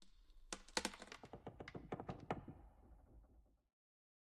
Minecraft Version Minecraft Version 1.21.5 Latest Release | Latest Snapshot 1.21.5 / assets / minecraft / sounds / block / pale_hanging_moss / pale_hanging_moss7.ogg Compare With Compare With Latest Release | Latest Snapshot
pale_hanging_moss7.ogg